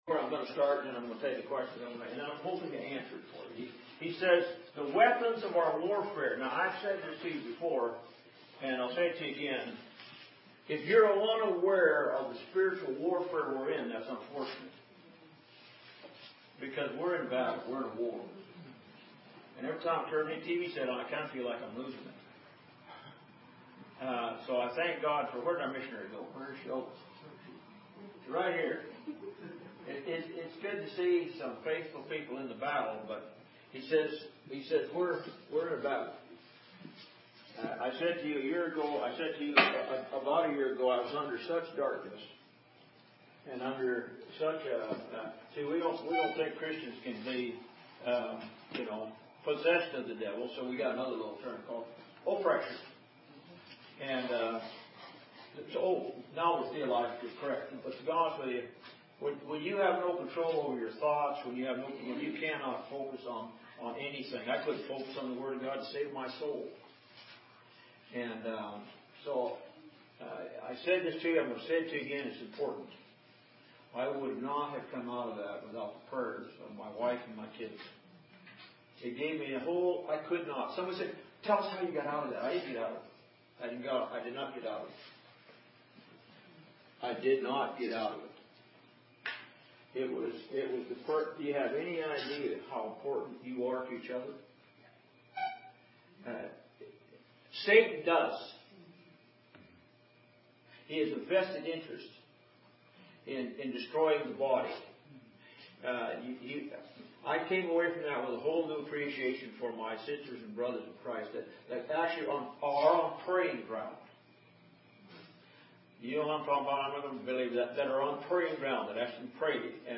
In this sermon, the preacher emphasizes the importance of knowing and understanding the Word of God.